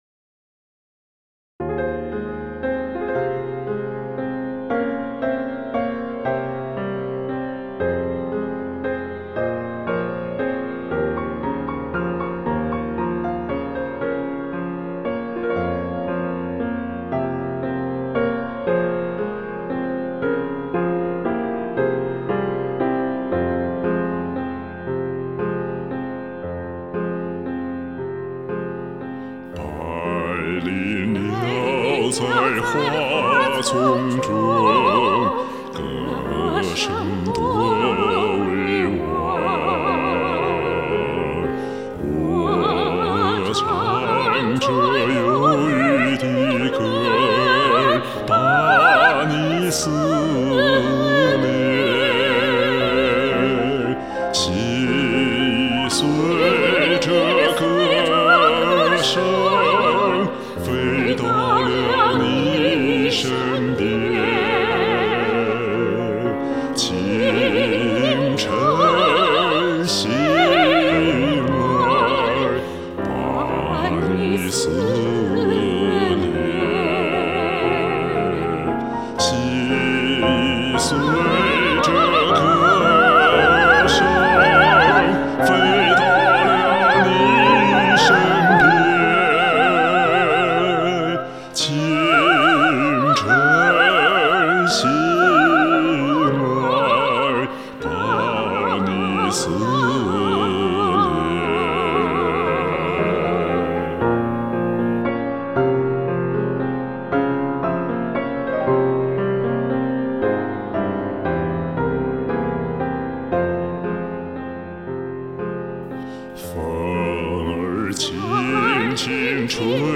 二重唱 新疆民歌《思戀》
這首歌是真正的男低音和女高音的結合。
加上這首歌本身就有近兩個八度的音域，所以，這首二重唱跨越了近四個八度（C2 - bB5）。男聲的音域在C2 - bB3，女聲音域在C4 - bB5。根據這首的范唱，以及網上找到的鋼琴伴奏譜，製作了這首bA調的鋼琴伴奏。